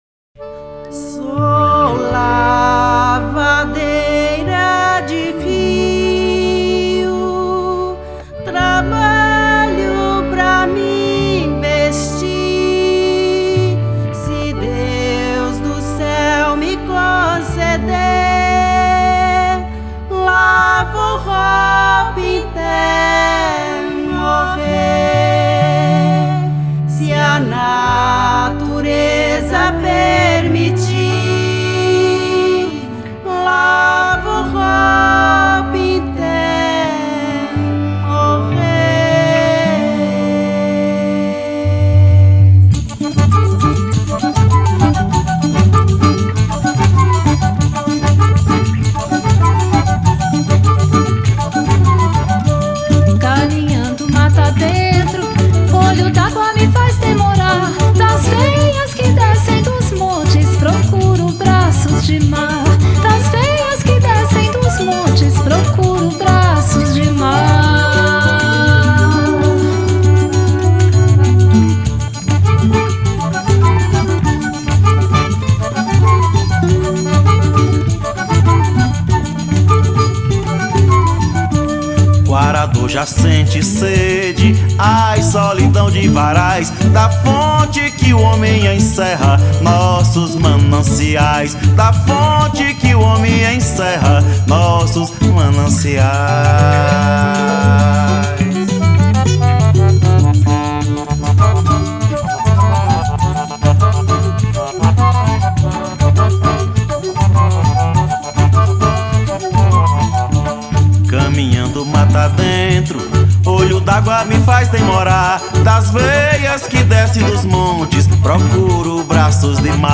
1592   03:35:00   Faixa:     Canção Nordestina